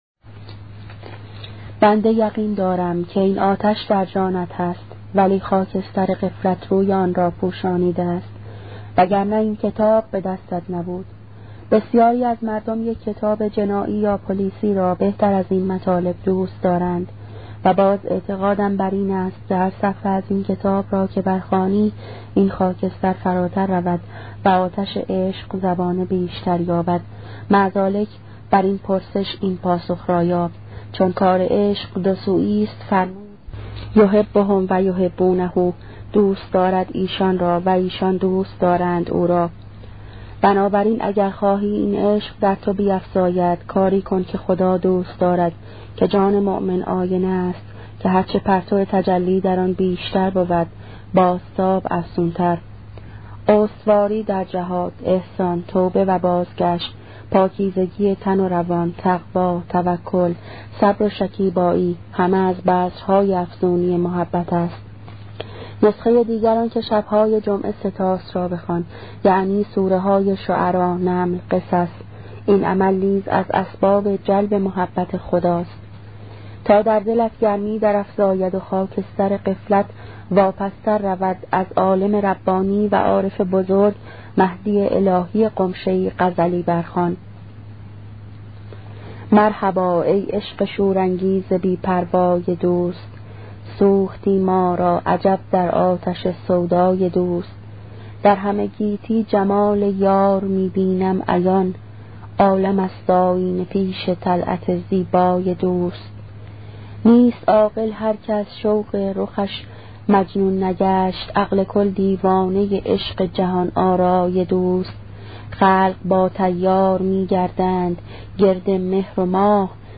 کتاب صوتی عبادت عاشقانه , قسمت هفتم